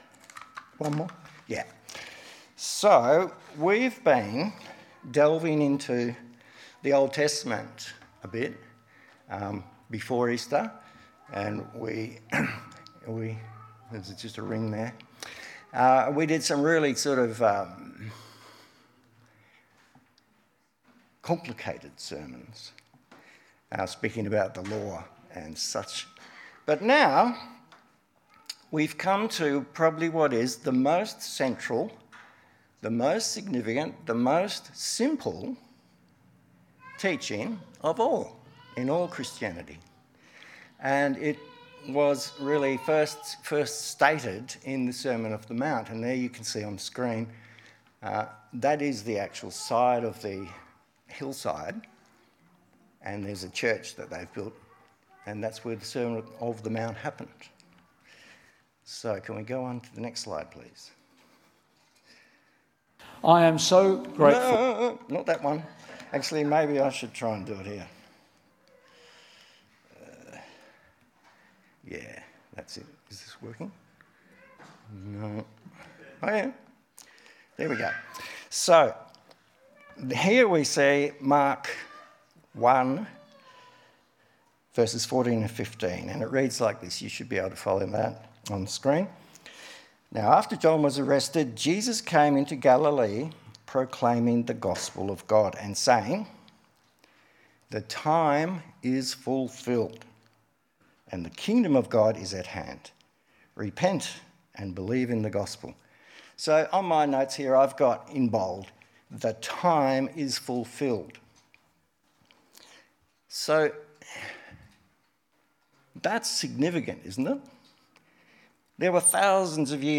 Passage: Matthew 5:1-12 Service Type: Sunday 10 am